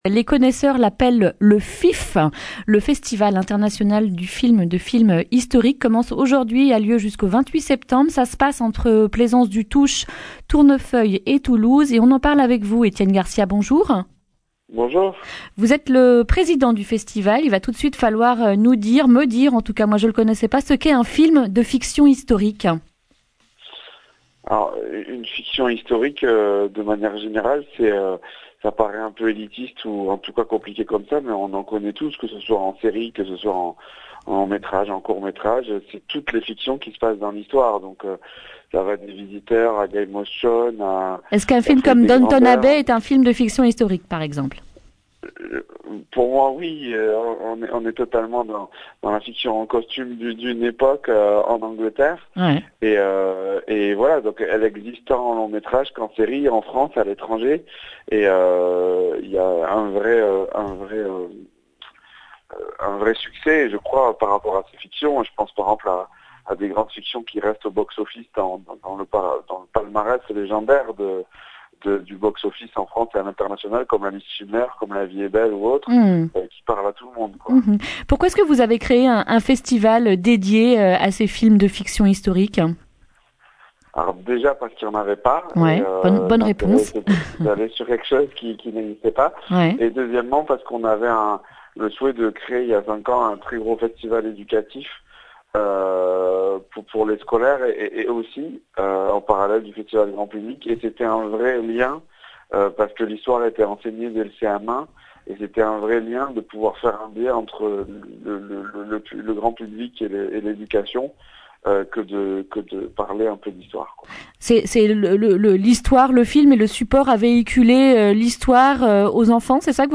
mercredi 25 septembre 2019 Le grand entretien Durée 11 min